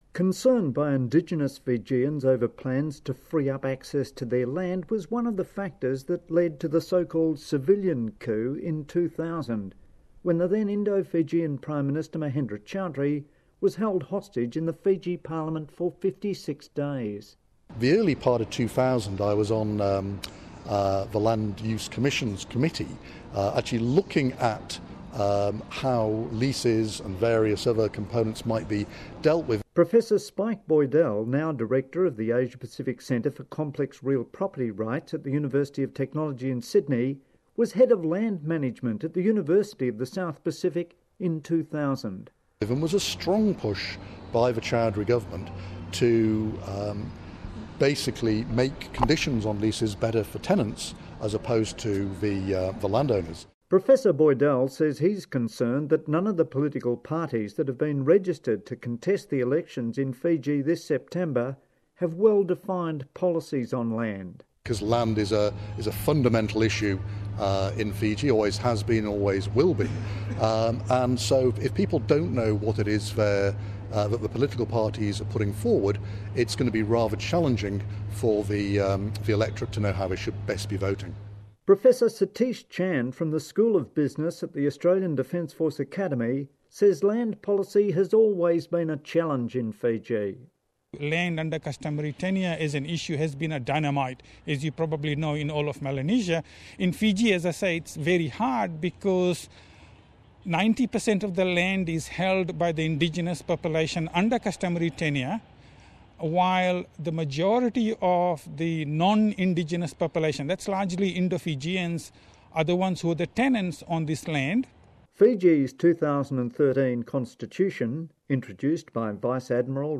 interviewed on ABC Radio Australia